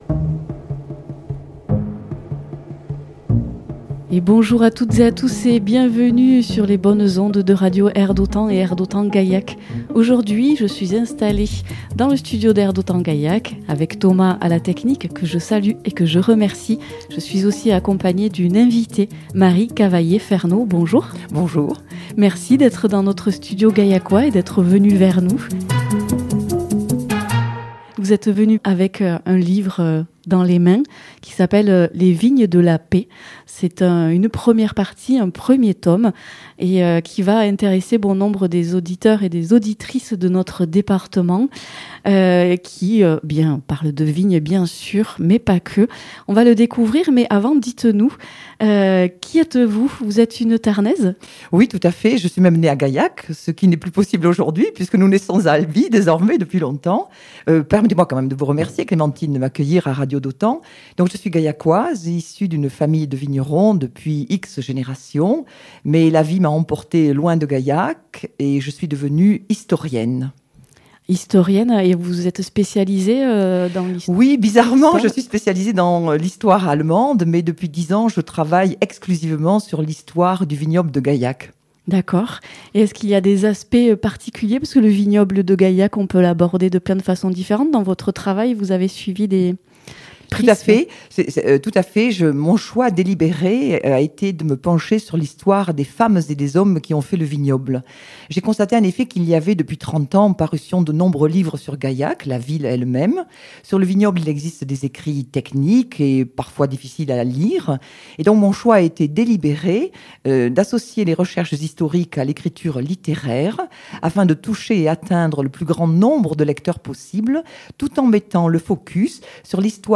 Découvrir la dernière interview de l’auteure